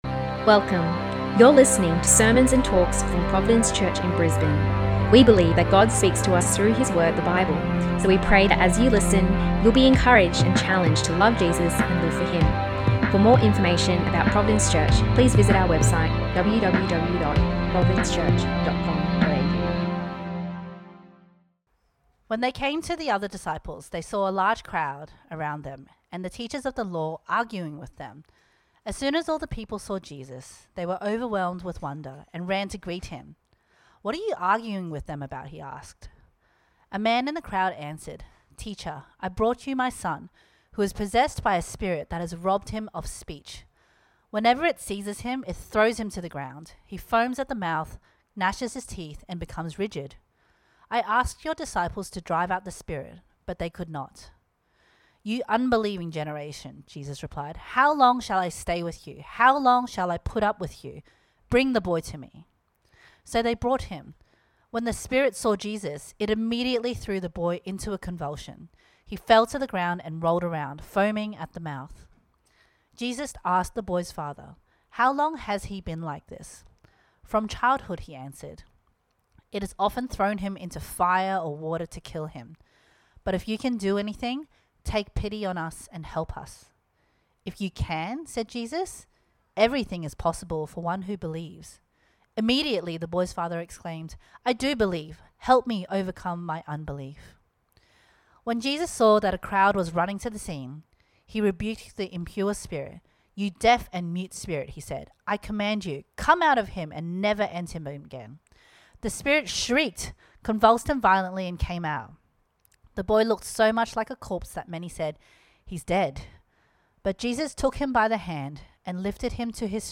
Mark-9-sermon2.mp3